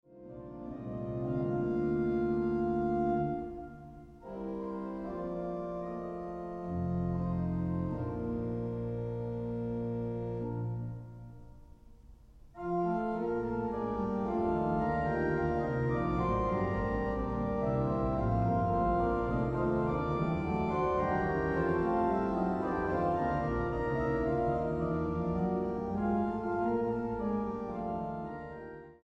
STYLE: Classical